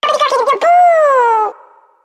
One of Yoshi's voice clips in Mario Party 6